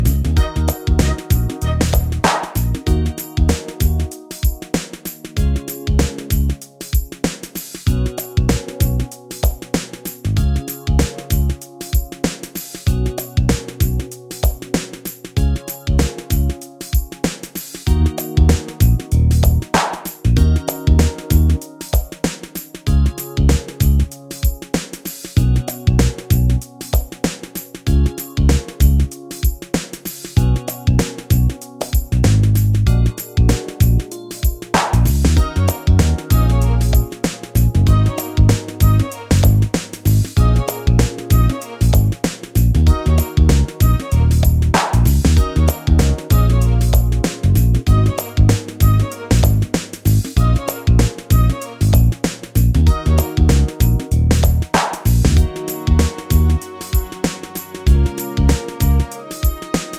no Backing Vocals Reggae 4:05 Buy £1.50